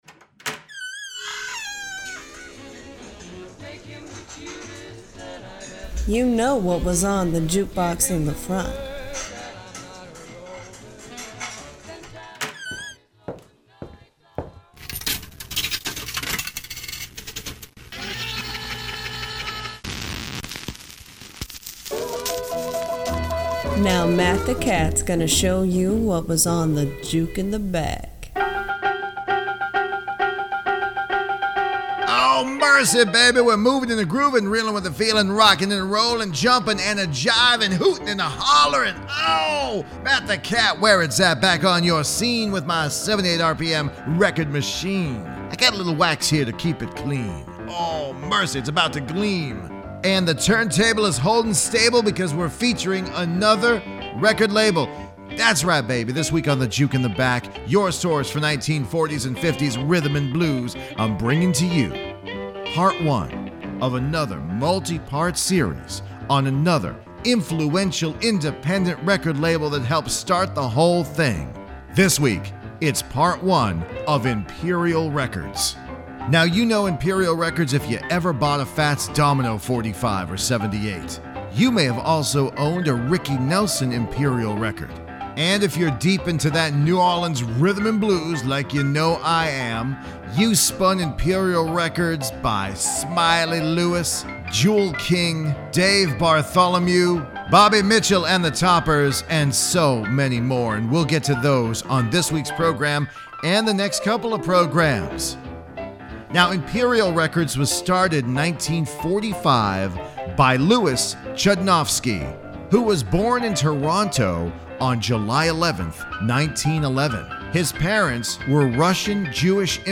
boogie woogie stylings
New Orleans blues